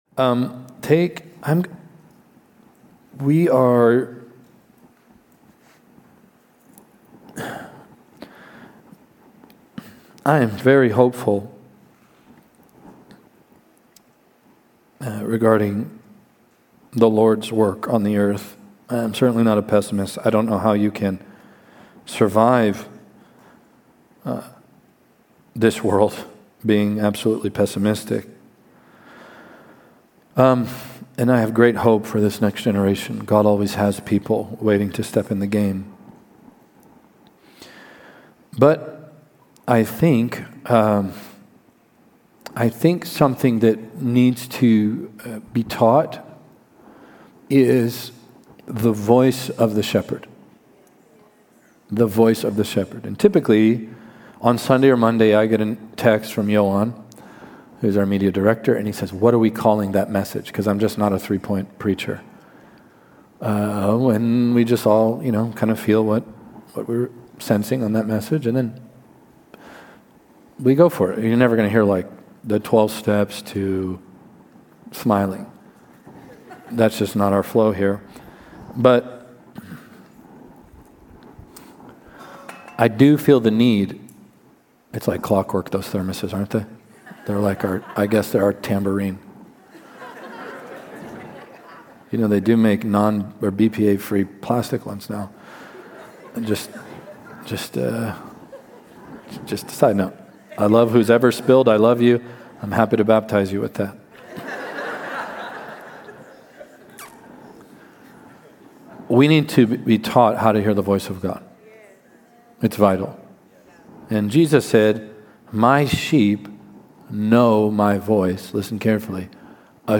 The sermon calls for a return to commitment and community in the church, contrasting it with the transient nature of modern church attendance.